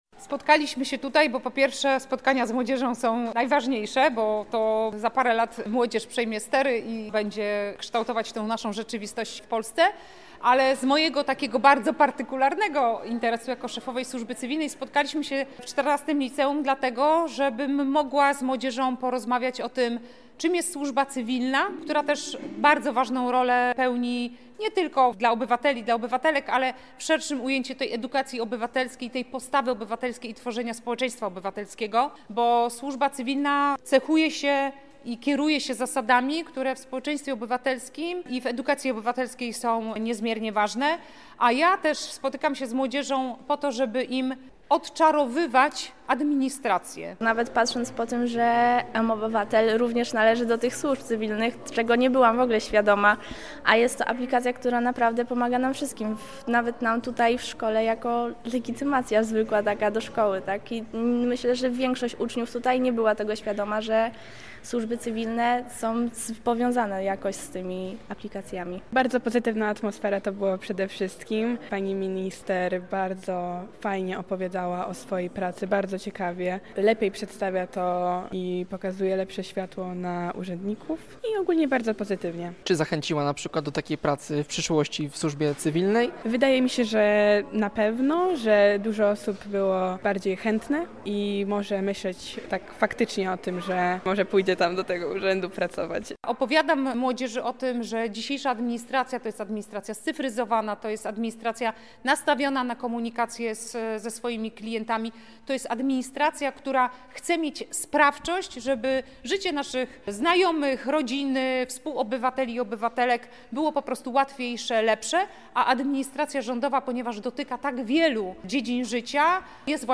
Praca w służbie cywilnej, funkcjonowanie państwa i rola urzędników w codziennym życiu obywateli – to główne kwestie, o których mówiła Anita Noskowska-Piątkowska, szefowa służby cywilnej, podczas spotkania z uczniami XIV Liceum Ogólnokształcącego w Gdańsku.